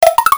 cartoon11.mp3